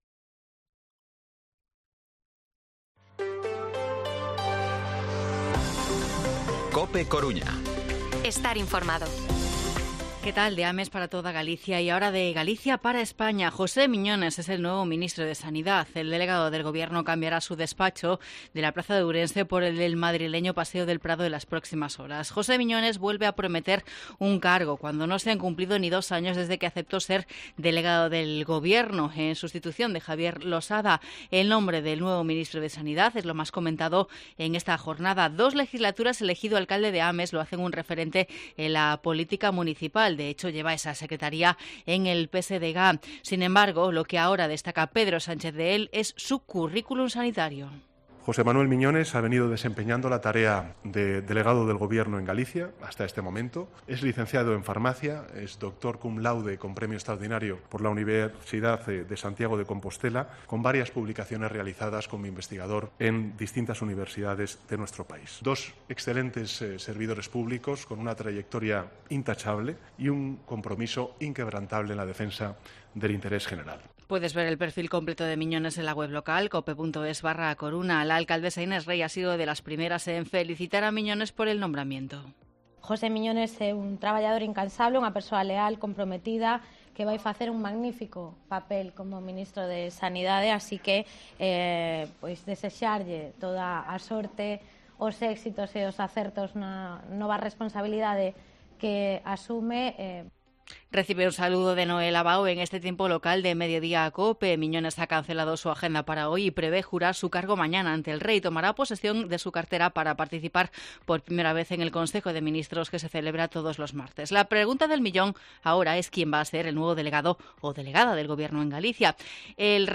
Informativo Mediodía COPE Coruña lunes, 27 de marzo de 2023 14:20-14:30